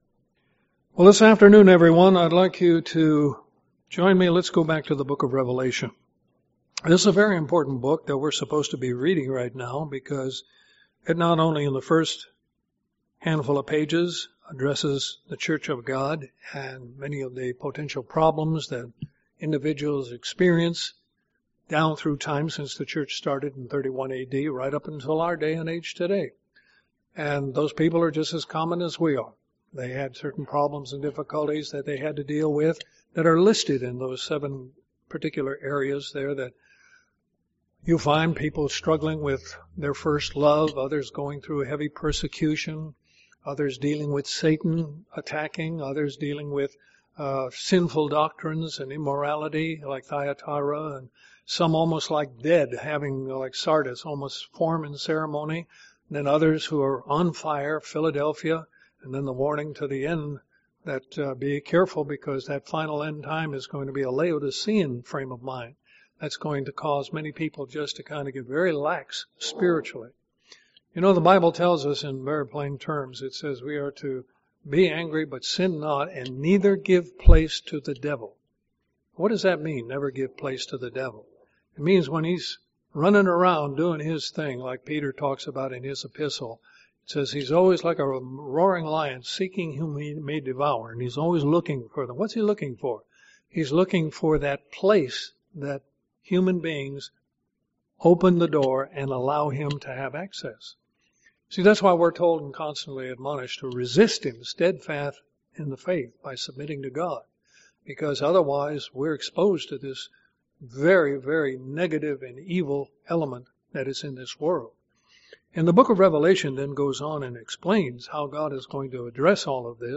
Sermons
Given in Columbus, GA